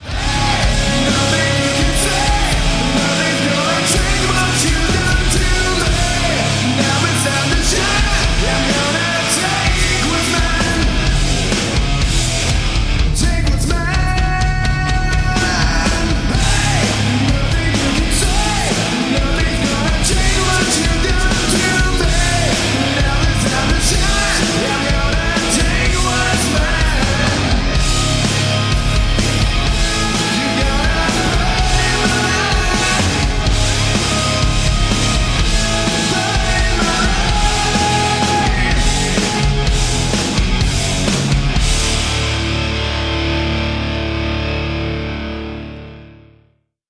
• Качество: 128, Stereo
громкие
брутальные
Драйвовые
электрогитара
Alternative Rock
Hard rock
мужские